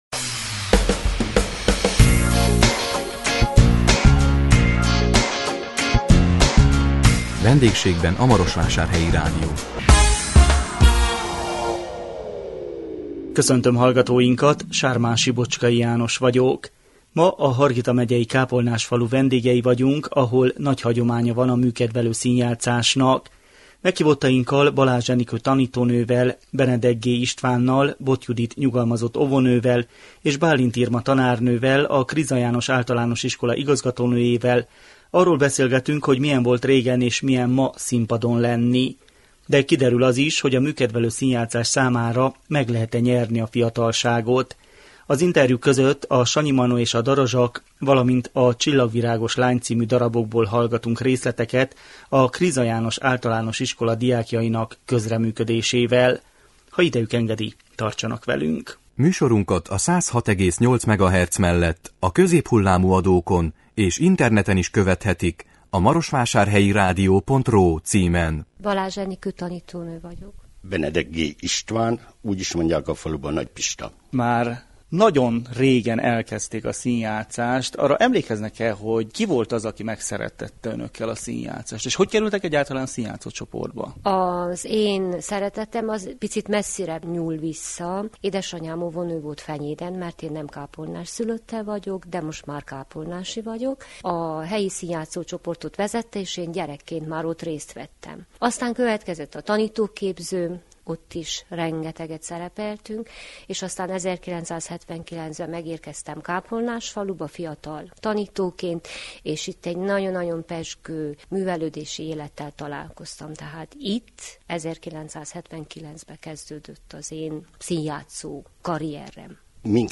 A 2017 január 26-án jelentkező Vendégségben a Marosvásárhelyi Rádió című műsorunkban a Hargita megyei Kápolnásfalu vendégei voltunk, ahol nagy hagyománya van a műkedvelő színjátszásnak.